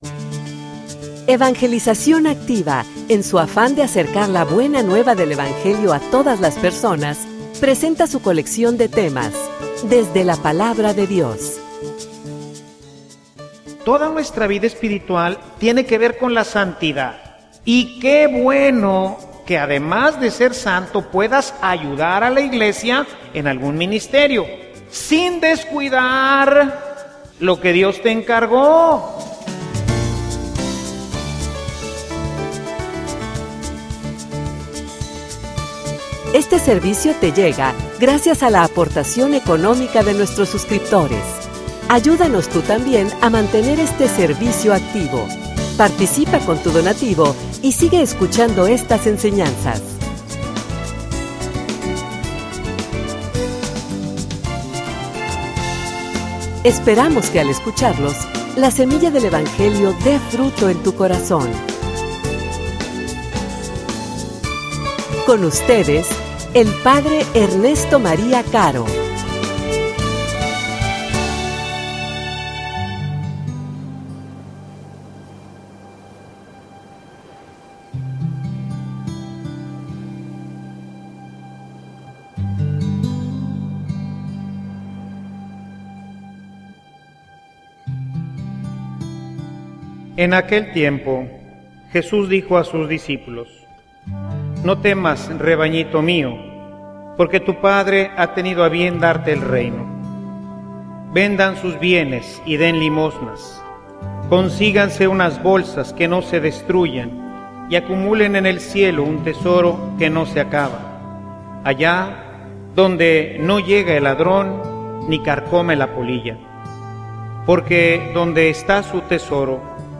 homilia_Realmente_estas_listo.mp3